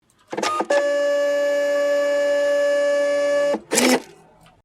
Free SFX sound effect: Lable Printer.
yt_QFvlwe8CRAY_lable_printer.mp3